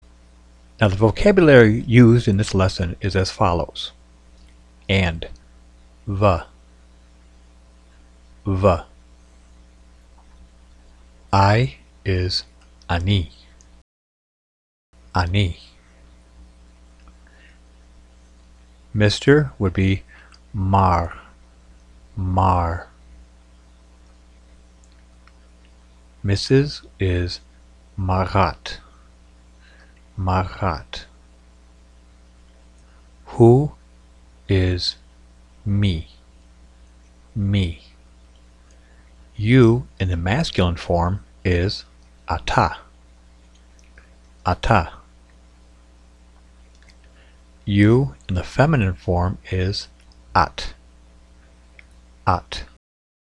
Before we begin, take some time to look over the vocabulary used in this lesson and listen to the audio for the pronunciation: